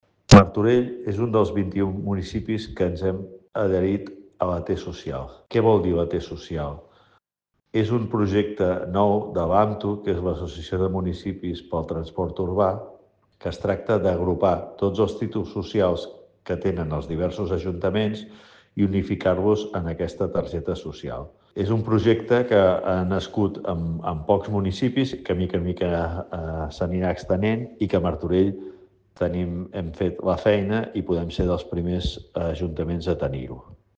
Lluís Sagarra, regidor de Mobilitat